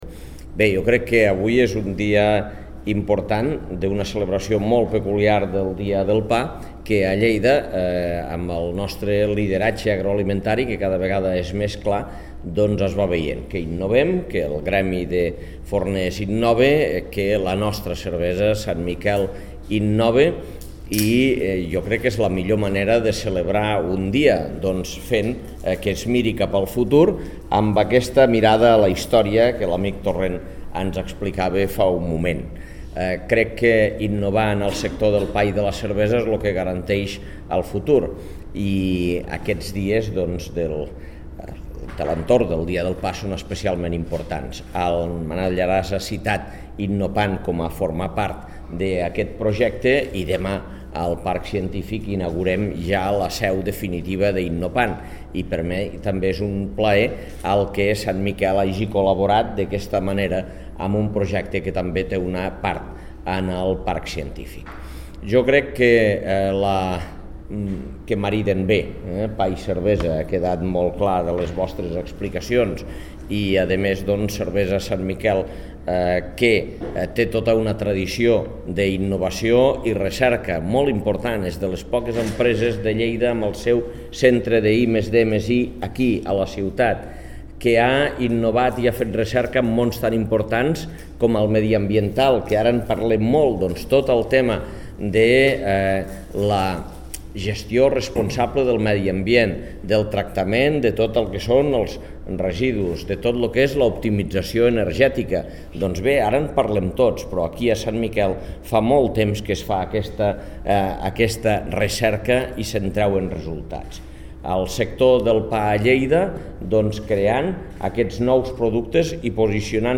L'acte l'ha presidit l'alcalde de Lleida Àngel Ros, que ha destacat l'aposta per la innovació que implica el llançament d'aquest nou producte i la incorporació de San Miguel a la fundació del Centre de Congressos i Convencions de la ciutat.